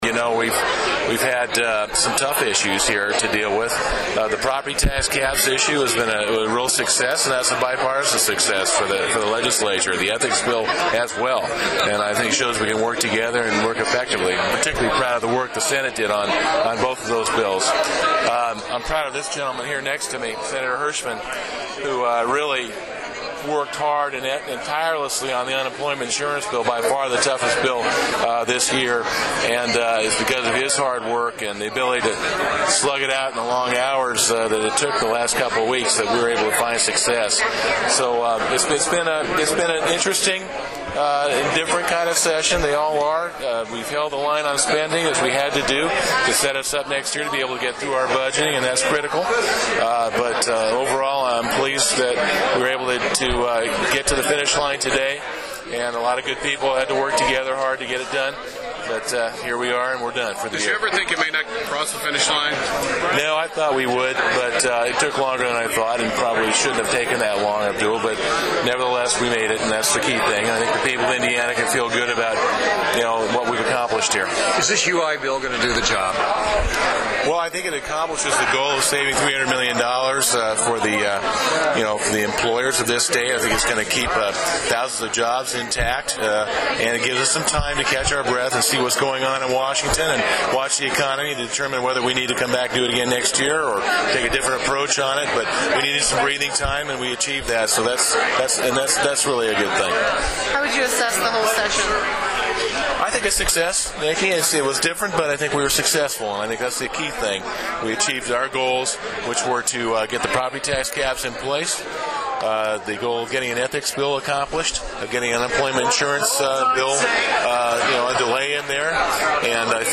I’m a little tired this morning after a long last day at the Legislature, so instead of me talking, I’ll let the lawmakers talk.